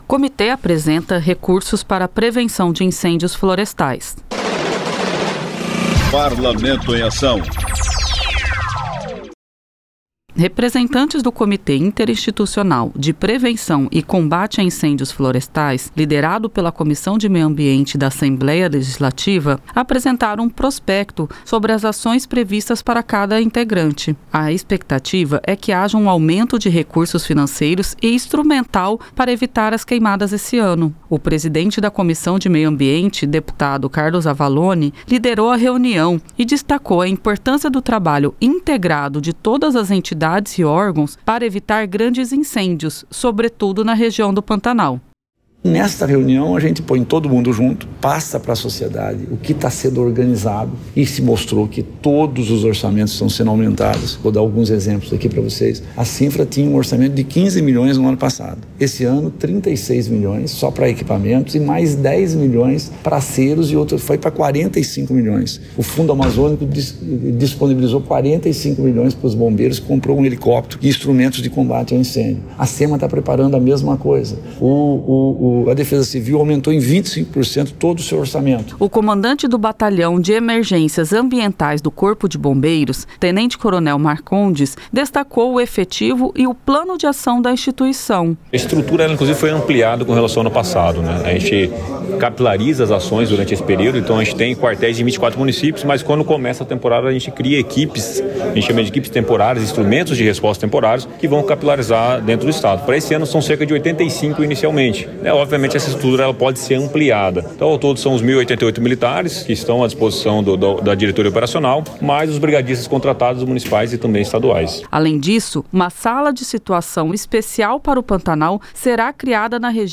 Reportagem